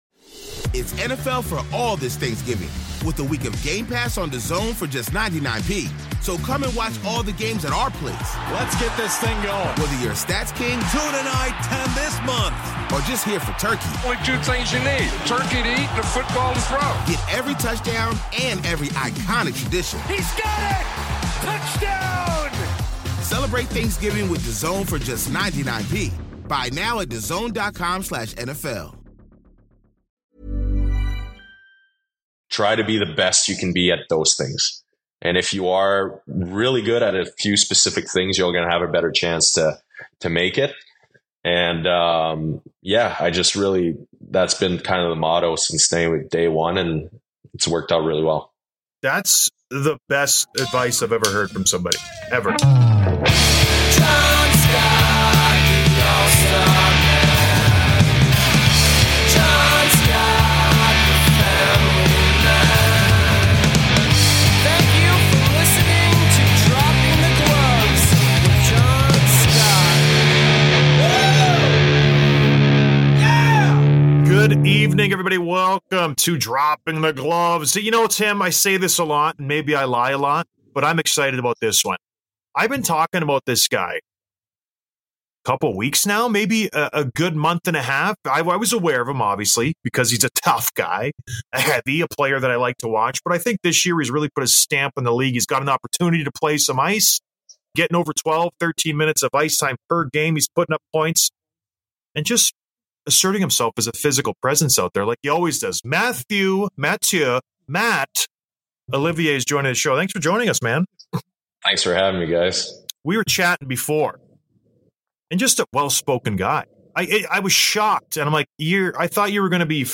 Interview with Mathieu Olivier, Columbus Blue Jackets